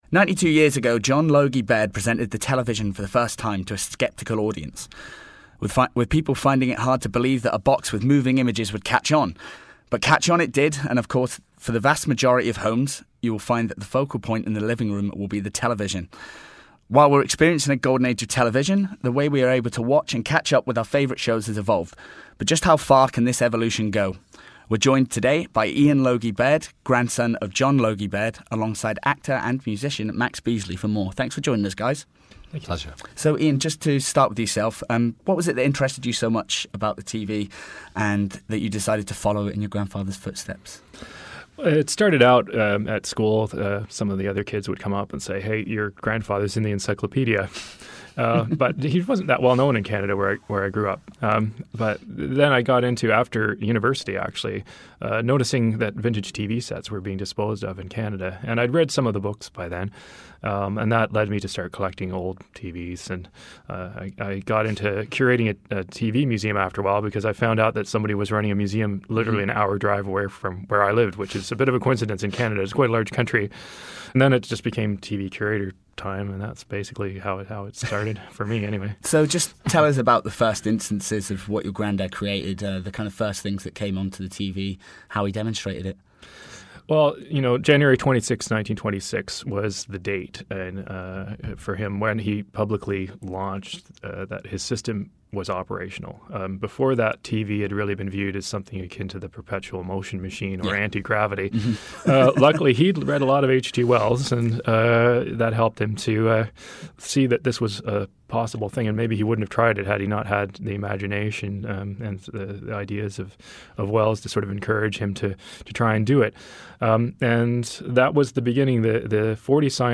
He joined us in studio to talk about how TV has changed during his time appearing on screen; from how it’s shot to how we watch it.